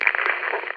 telephone